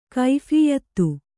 ♪ kaiphiyattu